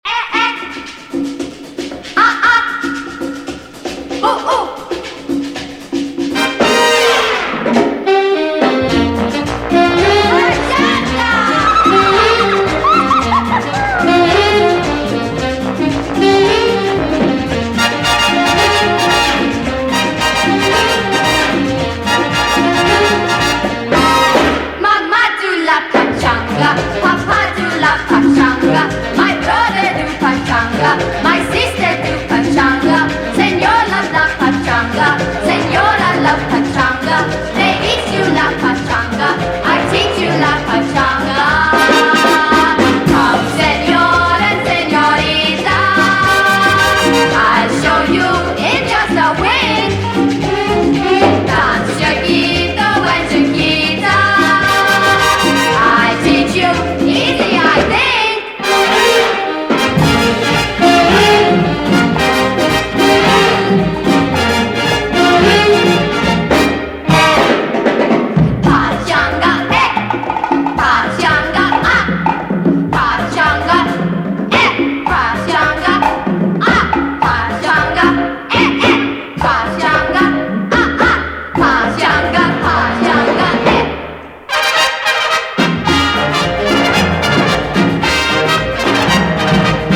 WORLD / LATIN / PACHANGA / CHARANGA / CHA CHA CHA / MAMBO
全曲楽しい61年チャランガ～パチャンガ・グルーヴ！